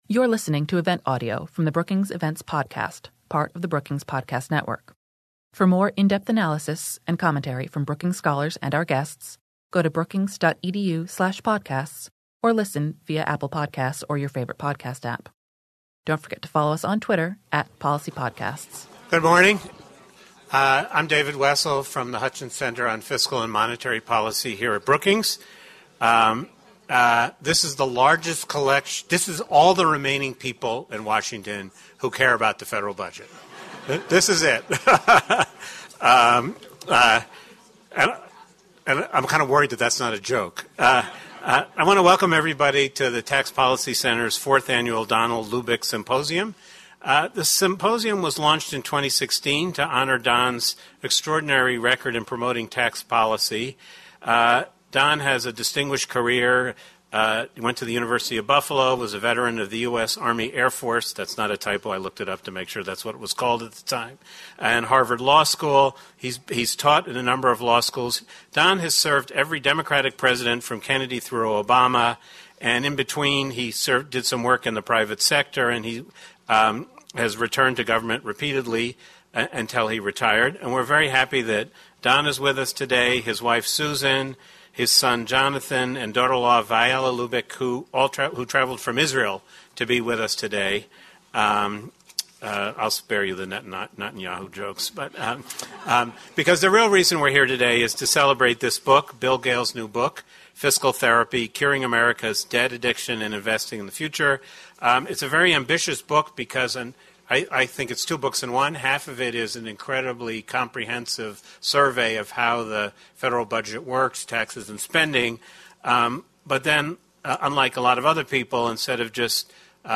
Book presentation
In his book, Gale explains that America faces two distinct but related challenges that policymakers must address in the coming years: tackling rising government debt while also restructuring taxes and spending for a more equitable and growth-oriented society. A keynote address and panel discussion deepened the conversation by addressing the following: How should we respond to growing fiscal challenges and still build a better future for ourselves, our children, and future generations?